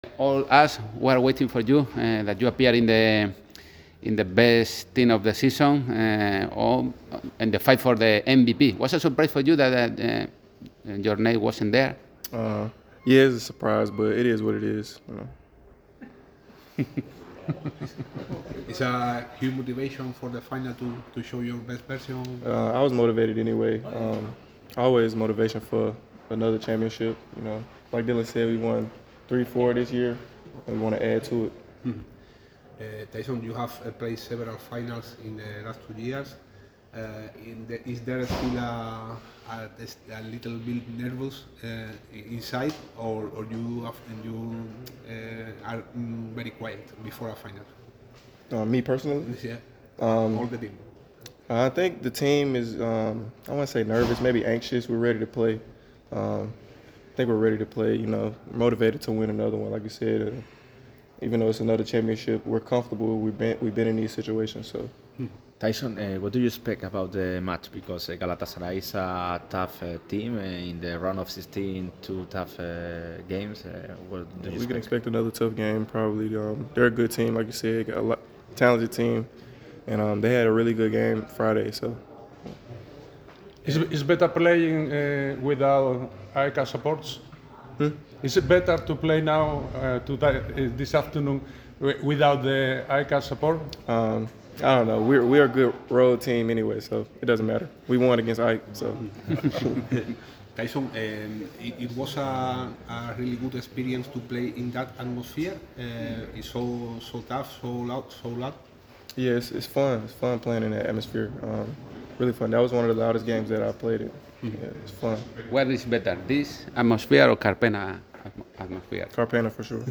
Tyson Carter frente a los medios antes de la final // Foto: Radio MARCA Málaga.